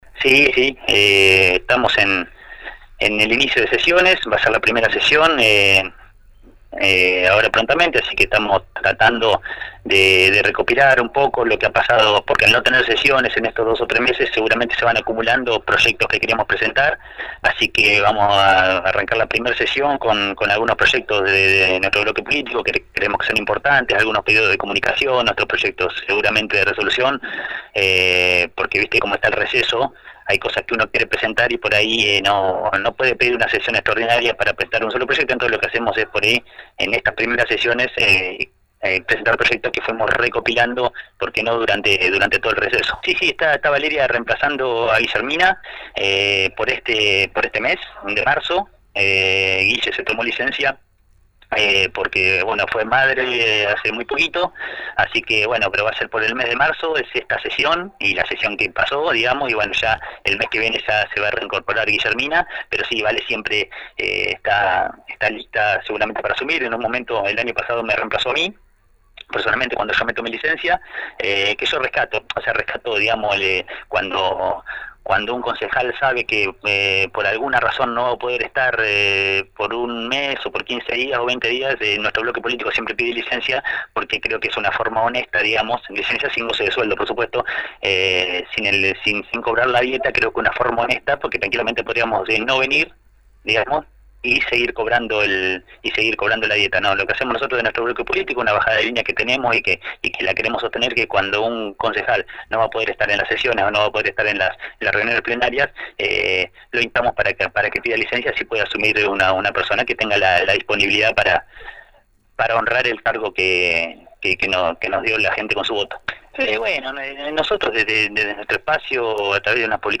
Este viernes en la 91.5 Lizarraga contó cómo se preparan para afrontar el año legislativo.